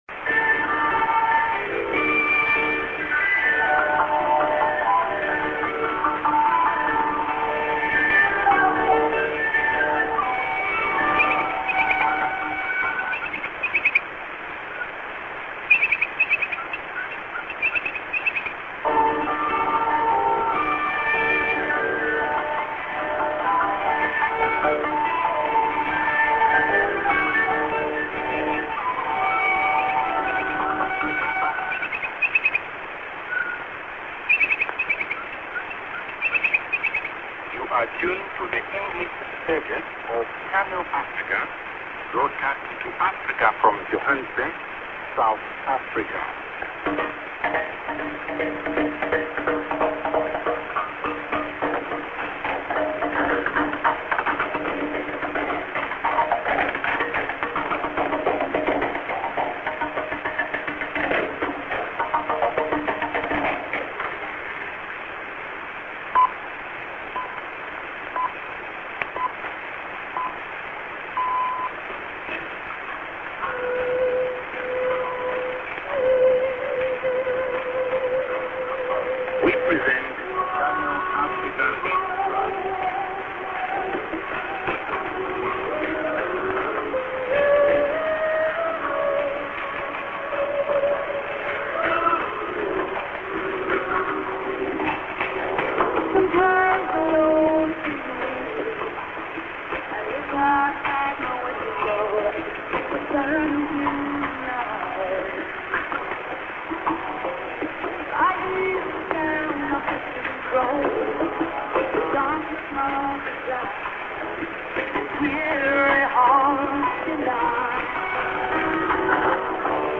Eng. St. IS+ID(man+man)->ST(duram)->01'08":TS->ID(man)->music->ID+SKJ(man)->